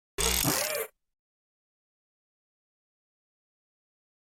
جلوه های صوتی
دانلود صدای ربات 21 از ساعد نیوز با لینک مستقیم و کیفیت بالا